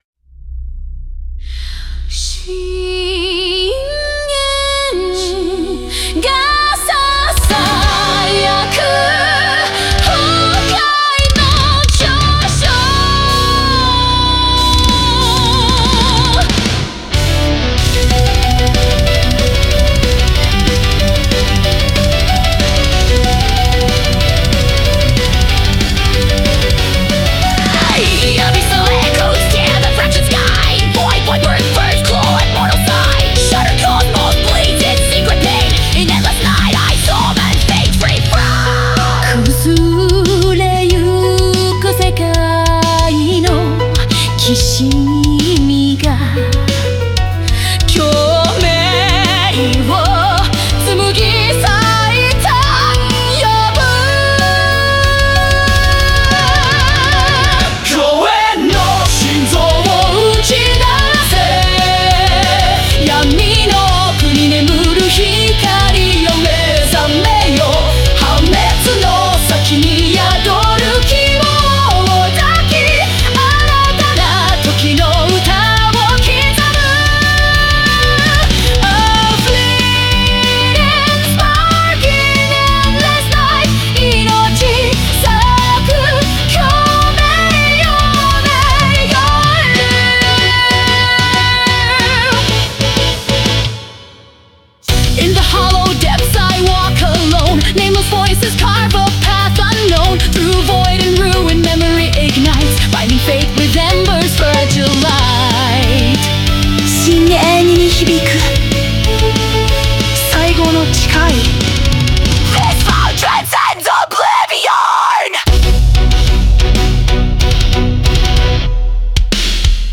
Symphonic Metal
Synth textures add otherworldly aura.